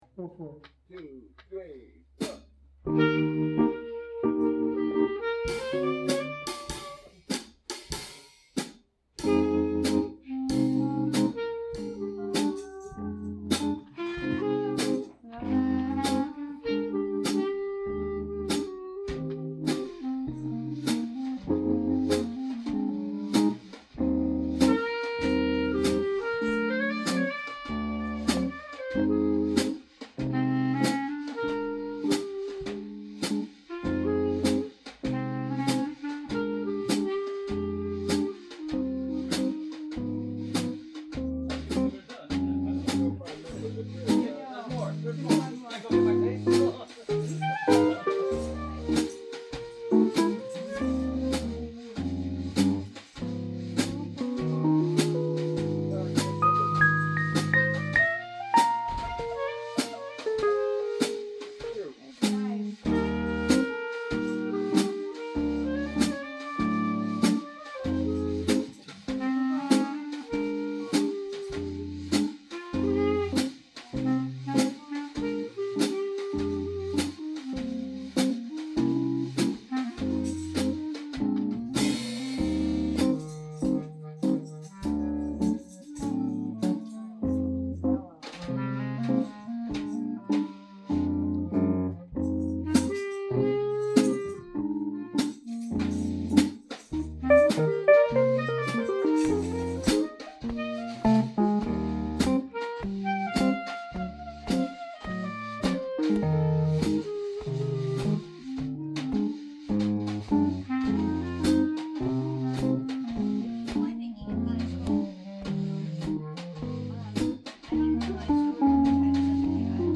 Arkestra – backyard jams – April 22, 2023